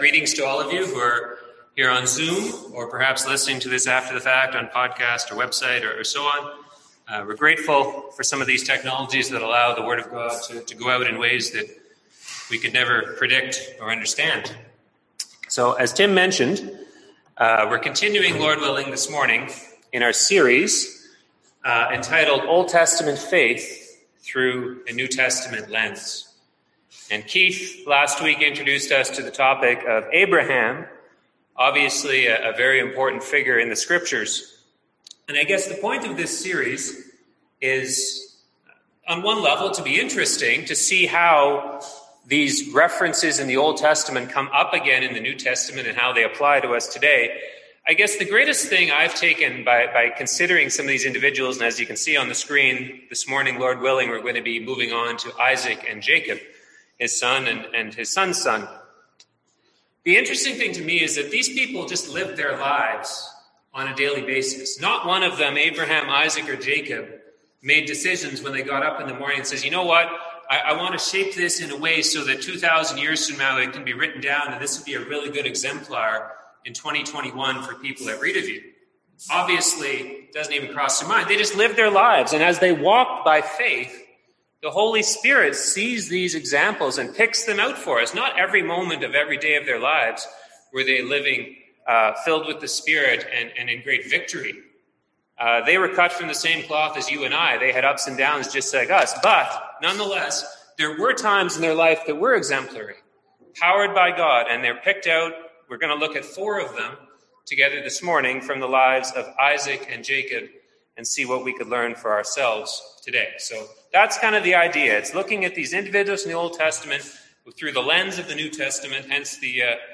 Series: OT Faith through NT Lens Passage: John 4:5-14, Romans 9:6-16, Hebrews 11:20-21 Service Type: Sunday AM